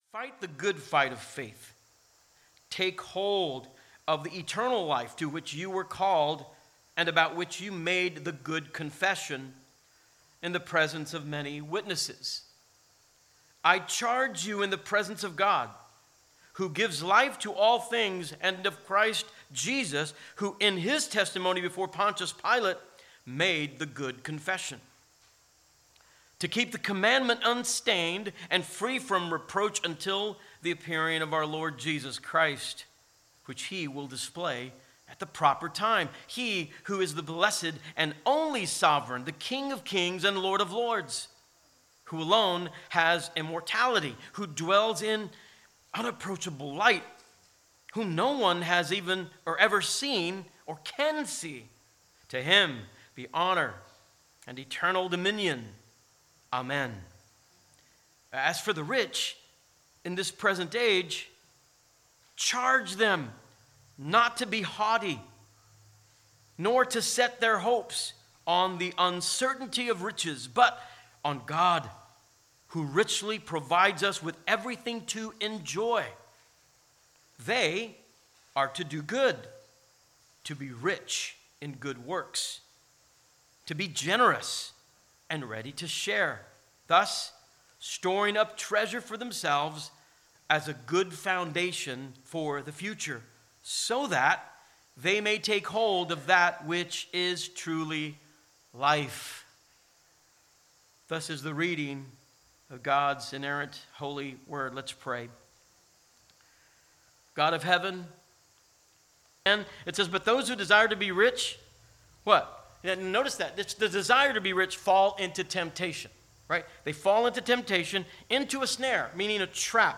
Sermons | Christ Redeemer Church